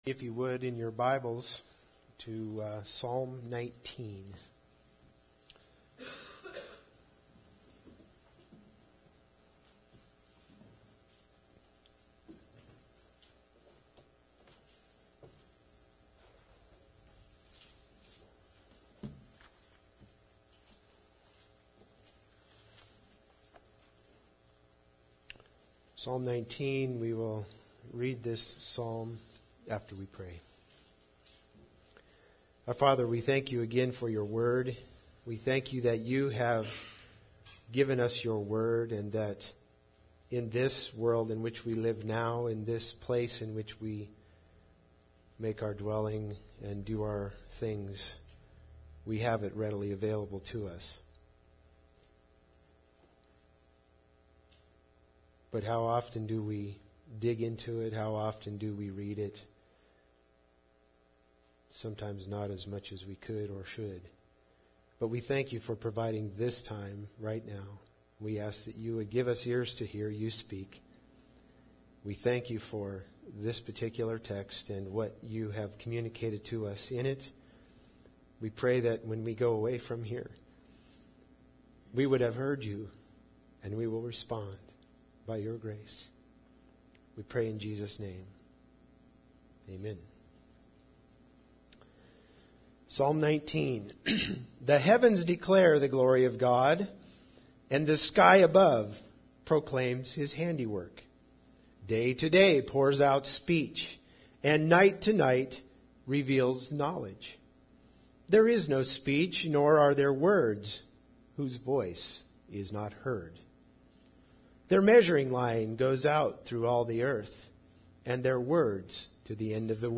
Psalms 19:7-11 Service Type: Sunday Service Bible Text